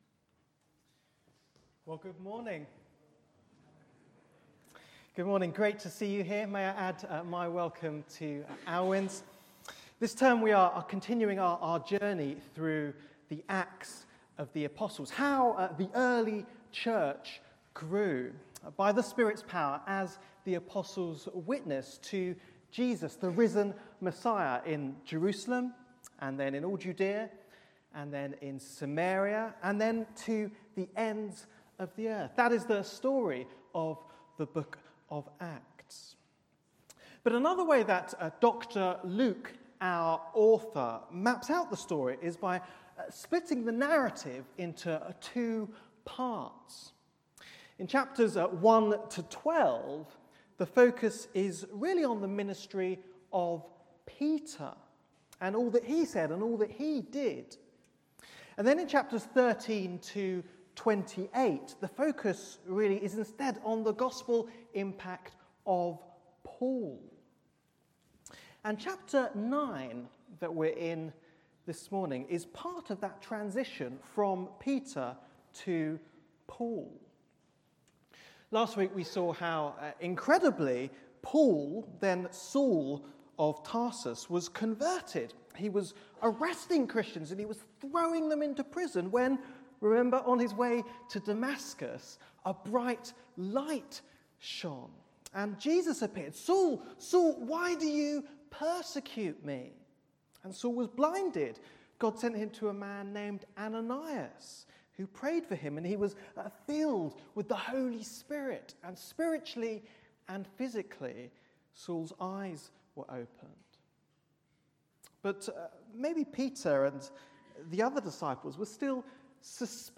Bible Text: Acts 9 : 32 – 42 | Preacher: